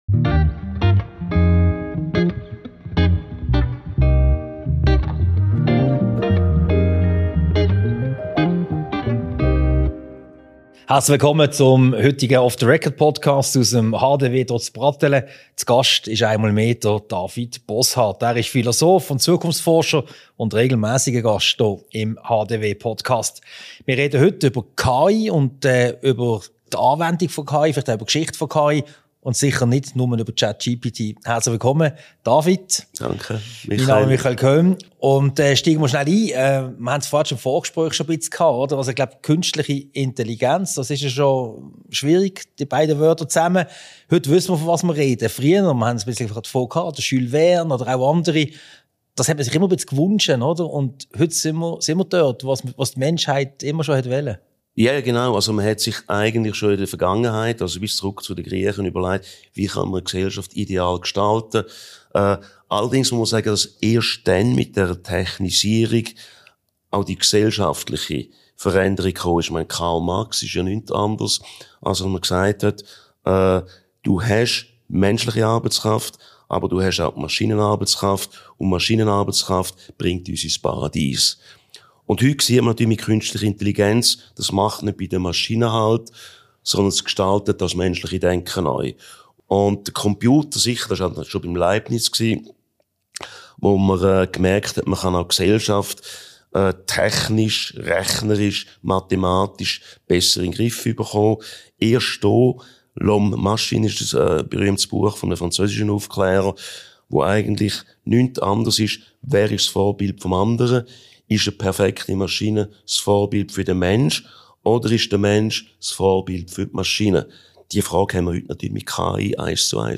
Diese Podcast-Ausgabe wurde als Video-Podcast im Sitzungszimmer Malawi im Haus der Wirtschaft HDW aufgezeichnet.